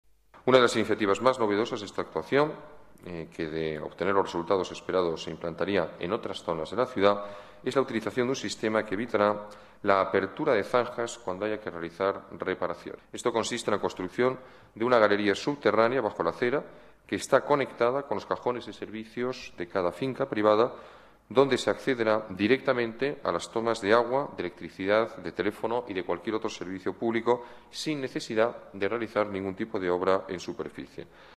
Nueva ventana:Alberto Ruiz-Gallardón, alcalde de la Ciudad de Madrid